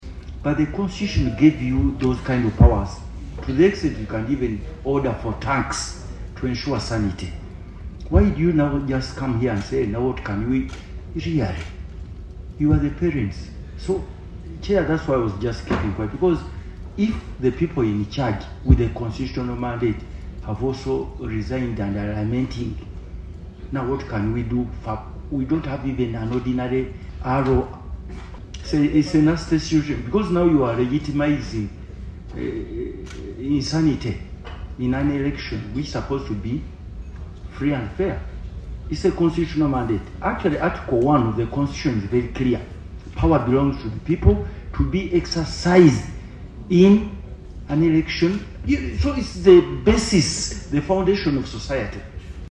The commission led by the Acting Secretary, Richard Kamugisha, on Tuesday, 27 May 2025 appeared before the Committee on Commissions, Statutory Authorities and State Enterprises (COSASE) chaired by Hon. Medard Sseggona.